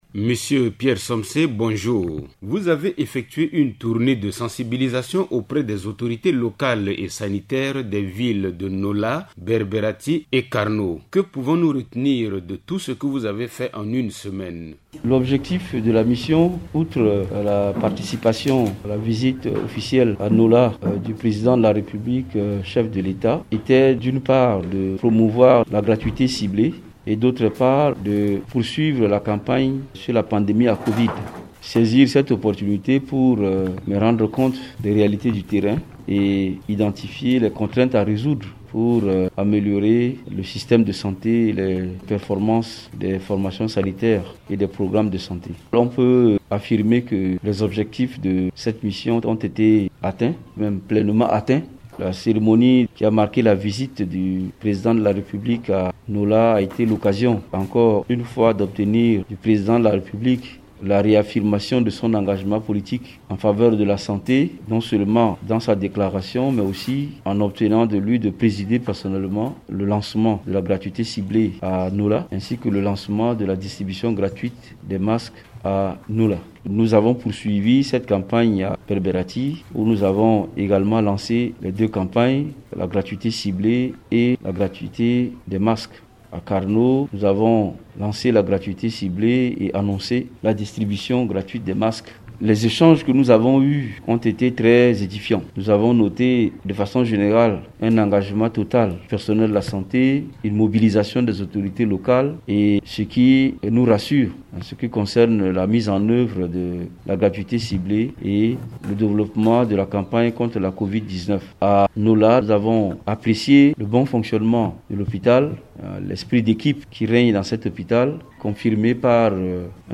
Dans une interview accordée à Radio Ndeke Luka, le ministre de la Santé publique, Pierre Somsé déplore l’ingérence intempestive des autorités préfectorales dans la gestion des hôpitaux, source de nombreux dysfonctionnements. Constat fait lors d’une mission de sensibilisation sur la gratuité ciblée des soins médicaux.